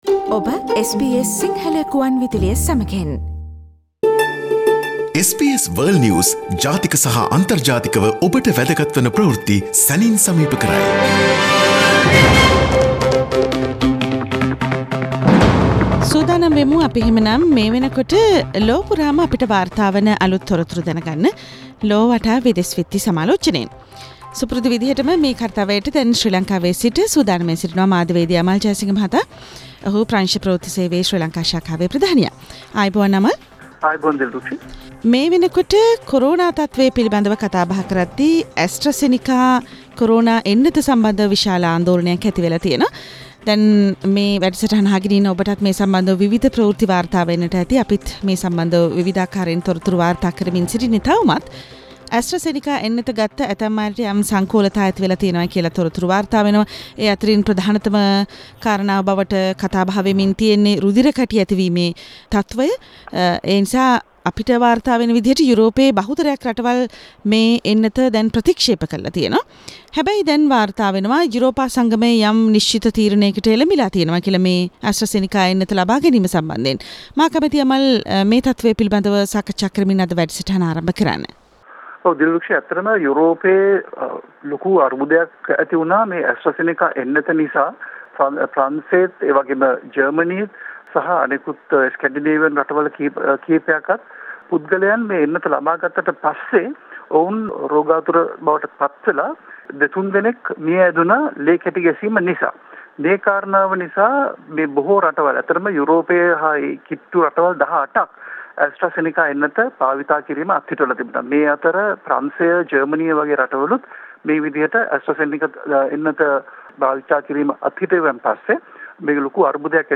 Listen to the latest news from around the world this week from our weekly foreign news review.